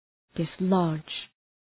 {dıs’lɒdʒ}